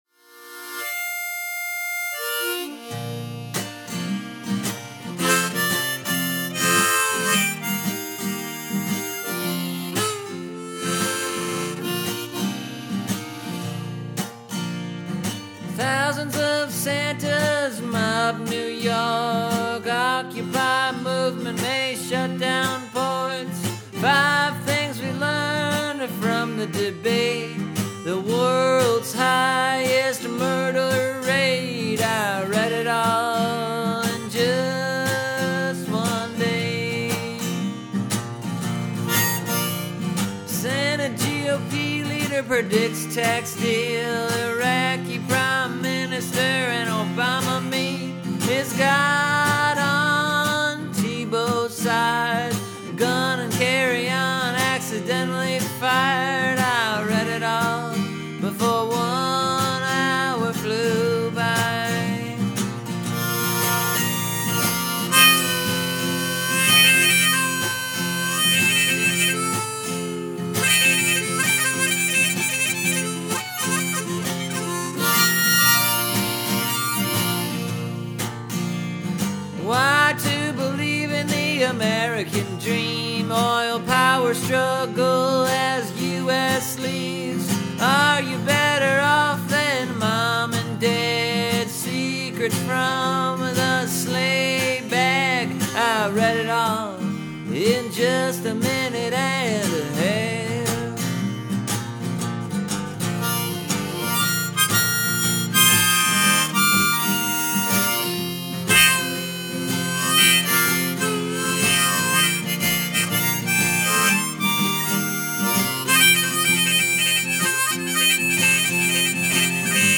Sometimes these talkin’ blues tunes are a little too easy to write, I think.